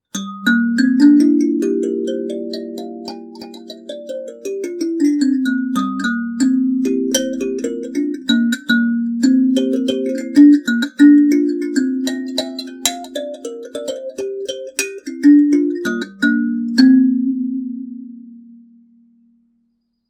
Sanza réalisée dans une boite recyclée de pichards; elle possède un résonateur métallique, une table en bois et 13 lames accordées en en do(C) hexatonique
Par sa forme et sa taille , la boite de pilchards se révèle un résonateur aux qualités acoustiques étonnantes, donnant un instrument au son timbré et puissant.
Avec une table en frêne, en noyer ou en padouk, elle est accordée le mode hexatonique de do (C), les lames sont munies de timbres.
Ci dessous accordage do (C) mixolydien hexatonique